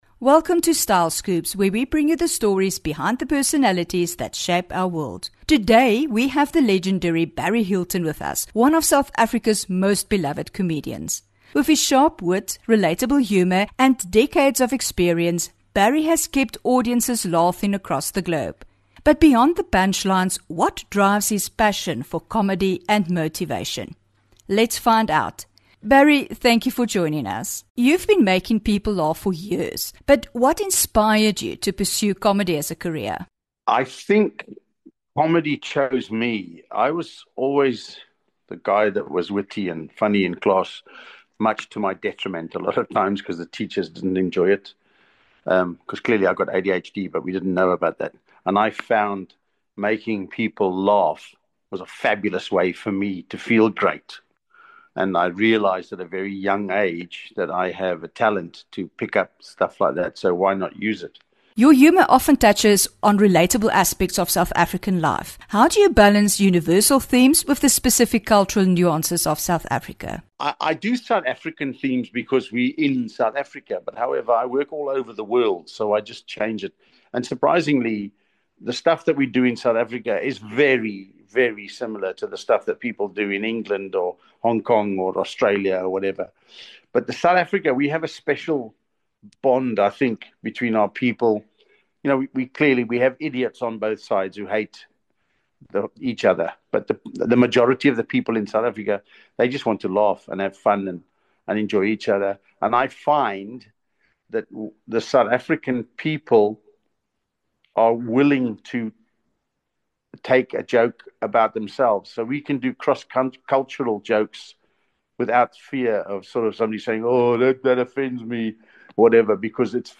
4 Mar INTERVIEW: BARRY HILTON ON HIS CAREER AS A COMEDIAN